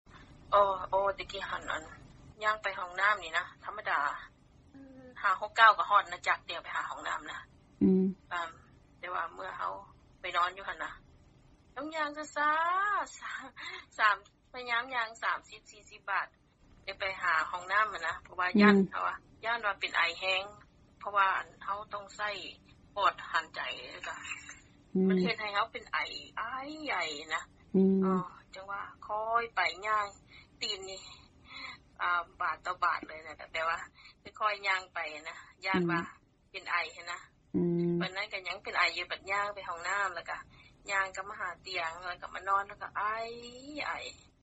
ສຽງ 2 ແມ່ຍິງທີ່ເປັນພະຍາດໂຄວິດ-19 ເລົ່າເຖິງການລະມັດລະວັງບໍ່ຢາກໃຫ້ໄອຫລາຍ